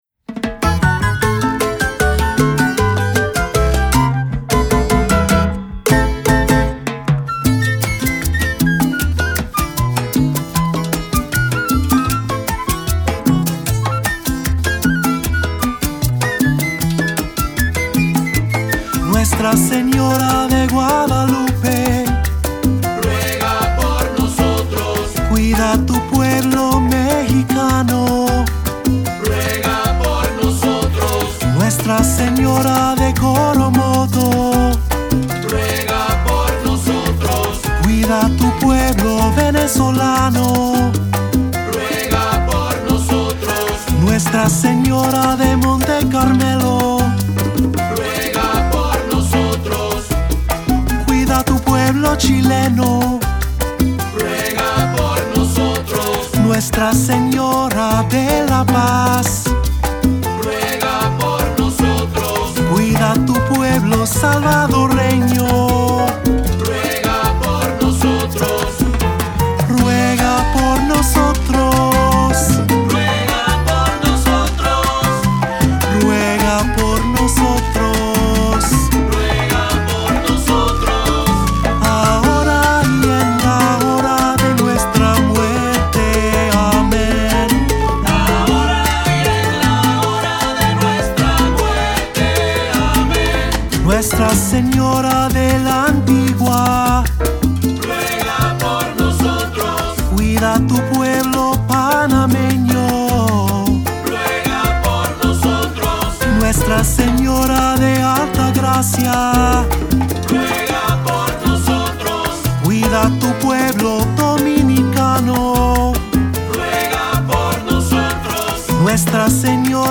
Voicing: Three-part; Cantor; Assembly